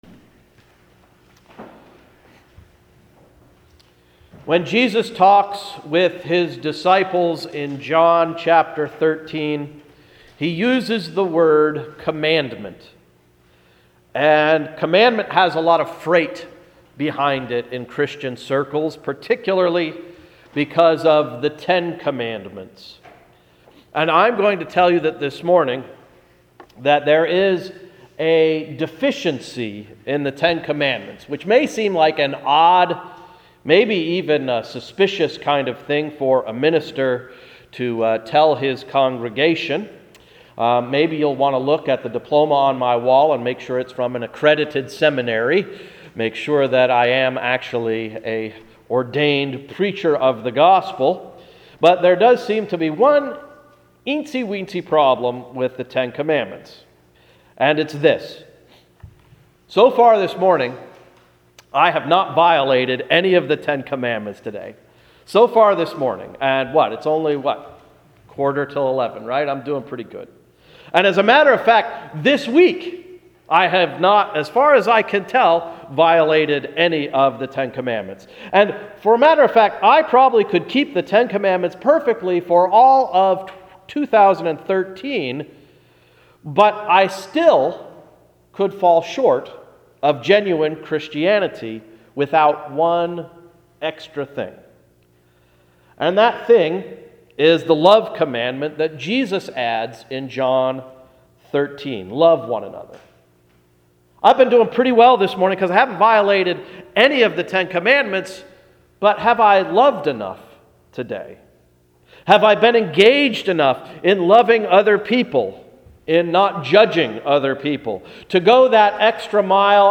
Sermon of April 28, 2013–“Grown in Love”
Welcome to Hill's Church Export, PA